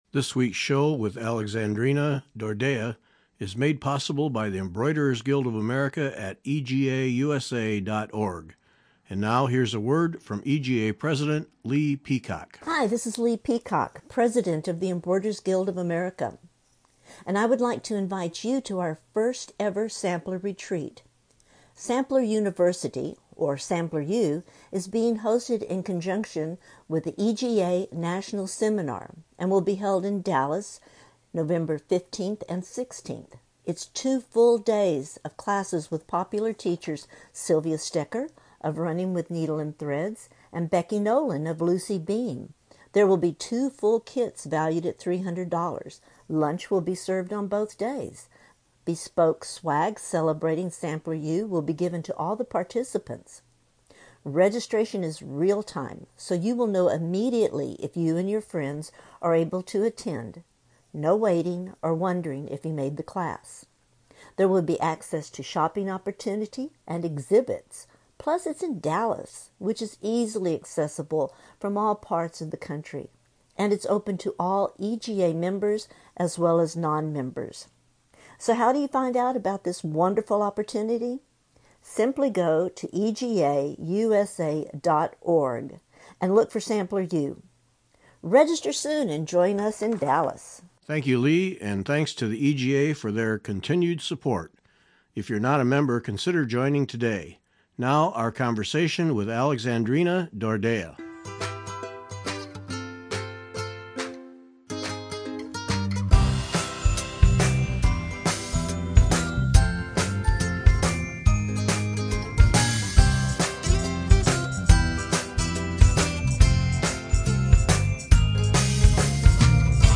In the conversation, we learn about her needlework journey, her design proce